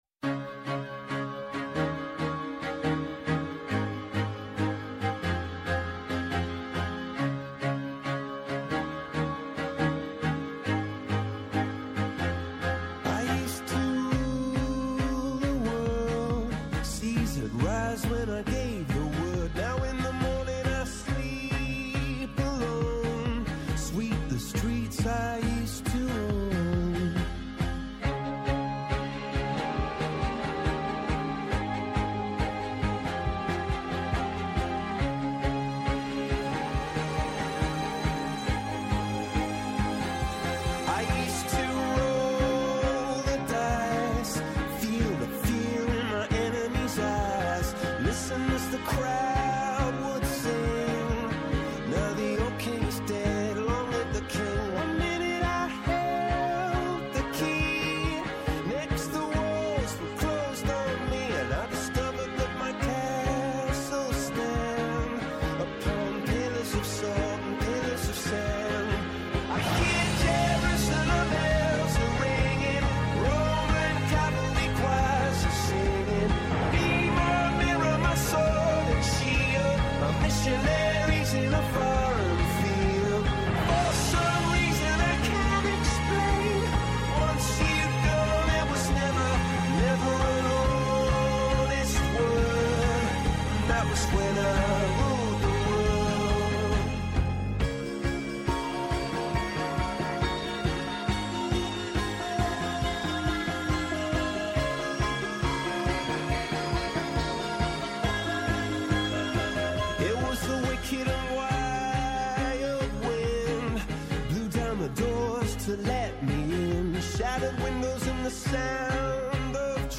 Κάθε Παρασκευή, στις 5 το απόγευμα και για ένα δίωρο, η εκπομπή «Γλυκιά ζωή» προβάλλει τα μοναδικά ελληνικά προϊόντα, τις ευεργετικές ιδιότητές τους και τις μορφές εναλλακτικού τουρισμού της Ελλάδας. Με έμφαση στη γαστρονομία, τον τουρισμό και τη διατροφή, «στρώνει το τραπέζι» με προσκεκλημένους αγρότες και παραγωγούς, διατροφολόγους και διοργανωτές εκθέσεων, σεφ και ξεναγούς.
Συνεντεύξεις